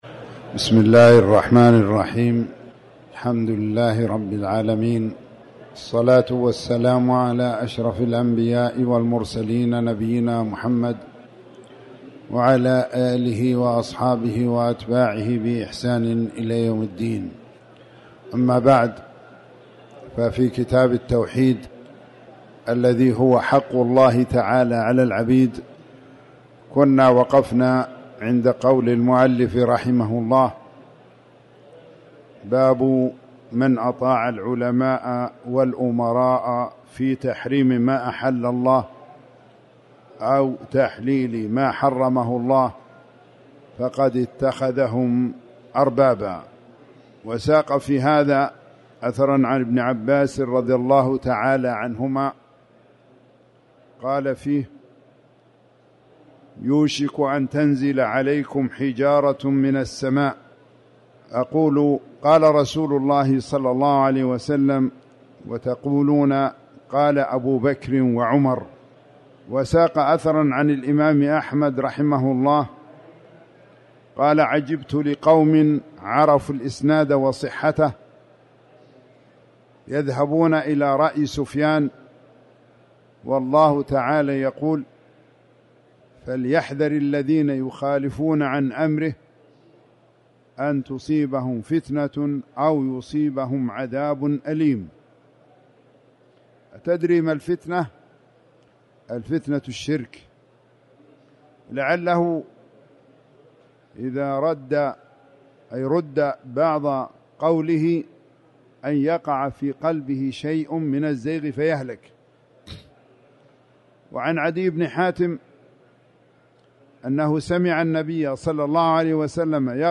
تاريخ النشر ٧ رمضان ١٤٤٠ هـ المكان: المسجد الحرام الشيخ